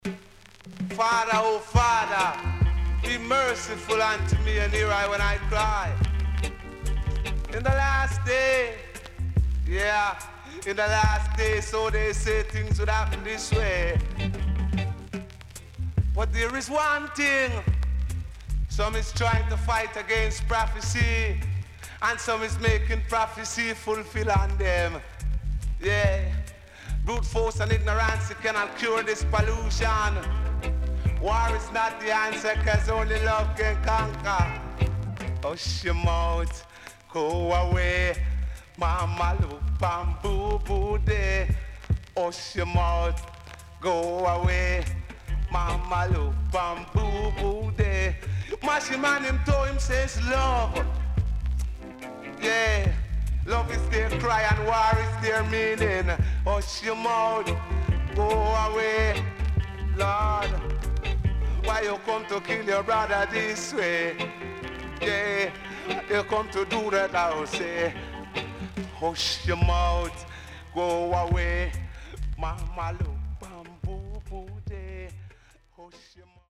SIDE A:全体的にかるいヒスノイズ入ります。